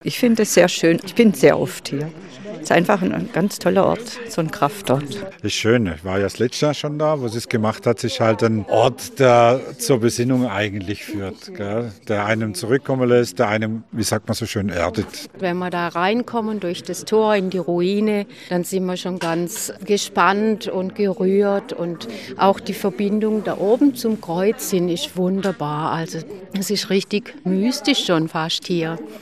So reagieren Besucherinnen und Besucher auf die besondere Krippe im Wald: